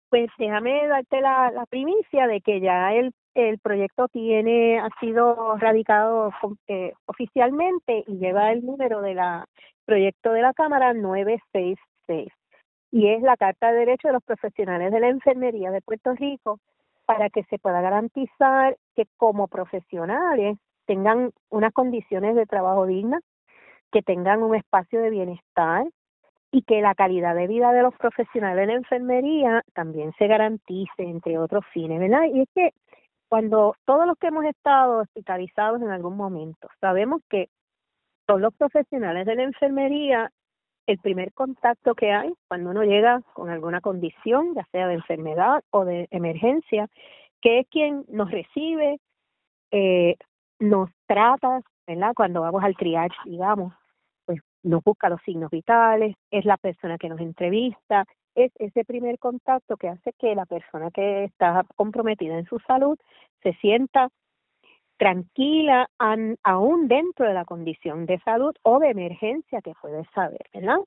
La legisladora explicó en entrevista con Radio Isla que busca que “se pueda garantizar que como profesionales tengan unas condiciones de trabajo dignas, que tengan un espacio de bienestar y que la calidad de vida de los profesionales de la enfermería también se garanticen“, y describió a estos profesionales como la piedra angular en el sistema de salud, dado a que son quienes constatan de cerca la condición de los pacientes.